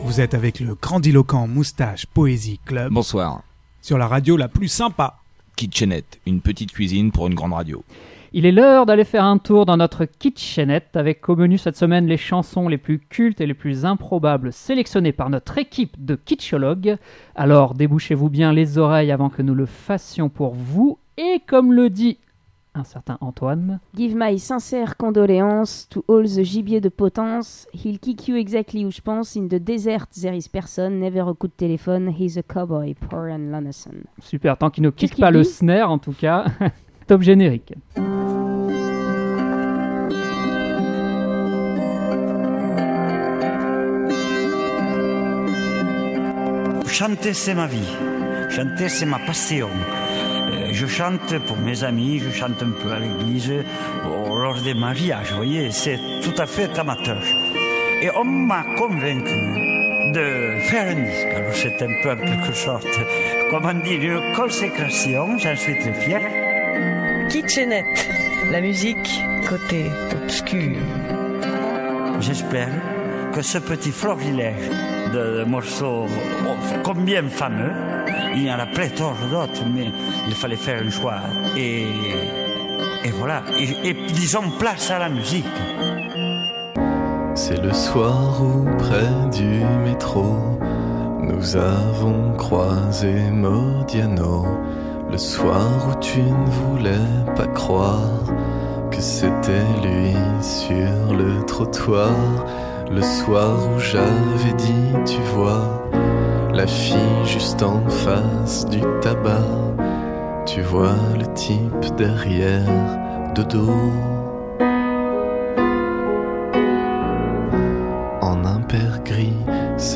Le direct du 15 décembre 2014